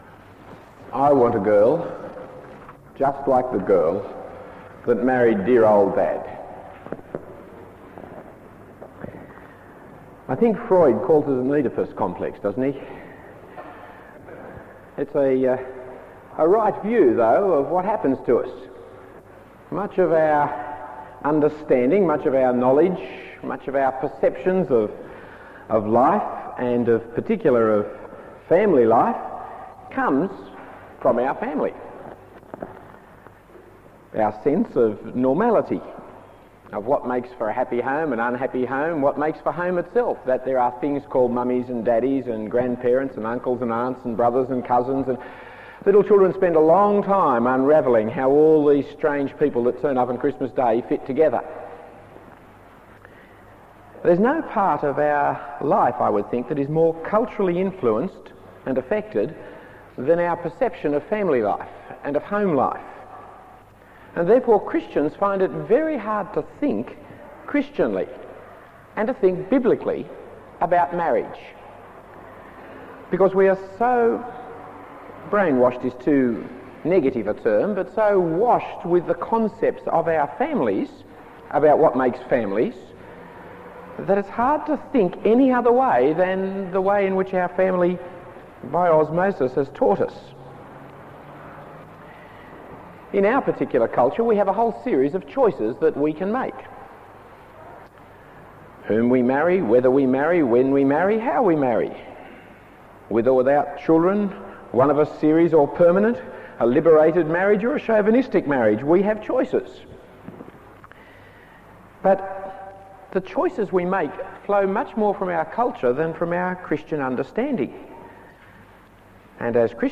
Talk 3 of 3 in the series Genesis 1988 St Matthias.